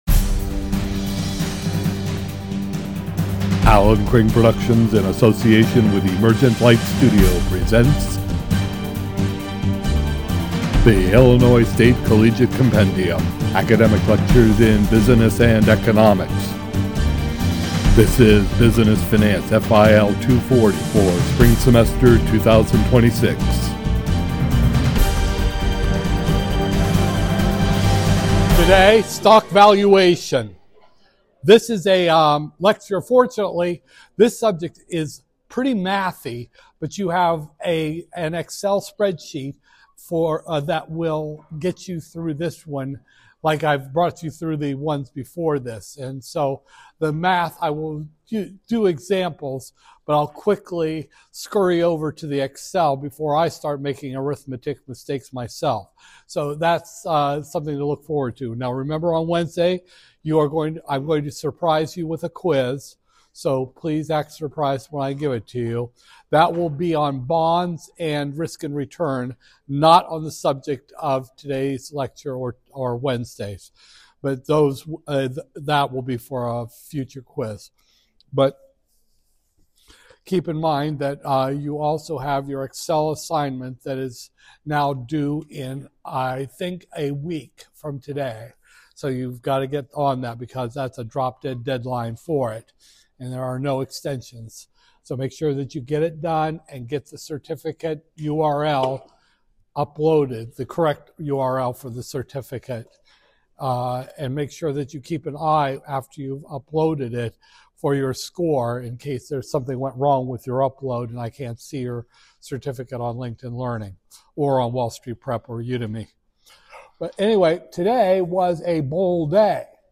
Business Finance, FIL 240-002, Spring 2026, Lecture 17